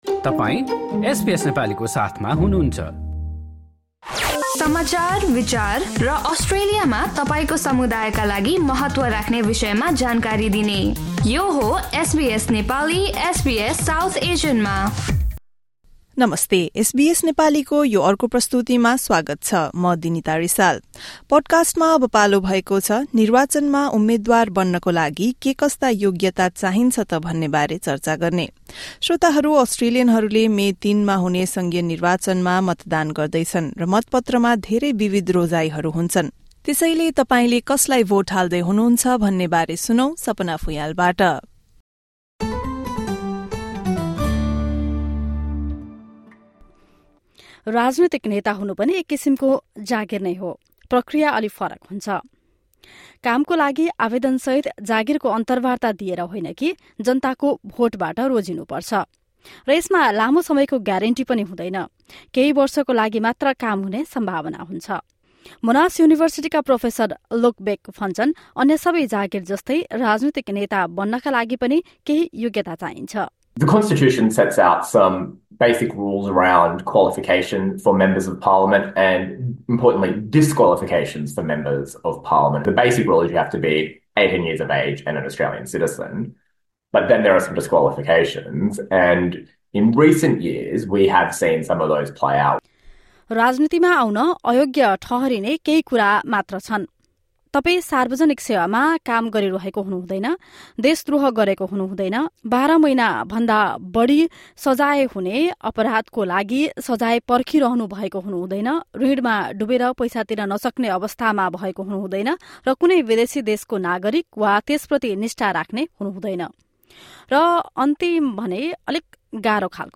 निर्वाचनमा उम्मेदवार बन्नको लागि के कस्ता योग्यता चाहिन्छ त भन्नेबारे यो रिपोर्ट सुन्नुहोस्।